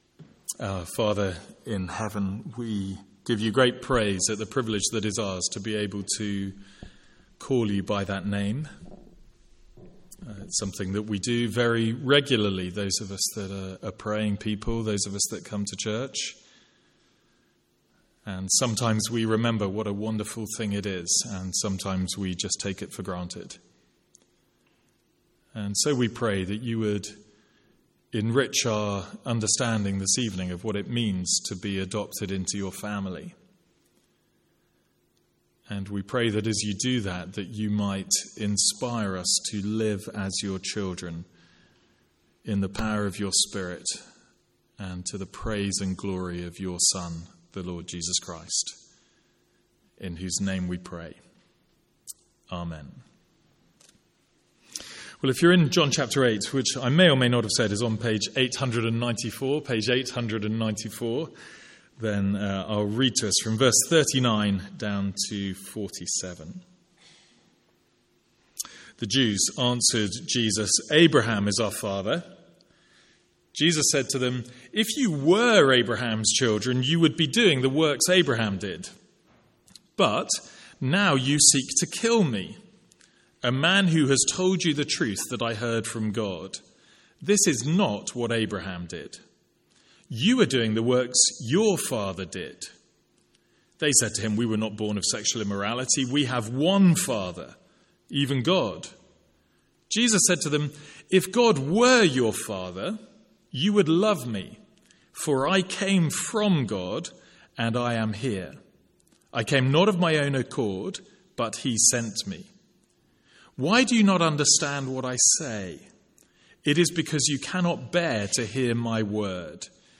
Sermon Notes
From our evening series on the Order of Salvation.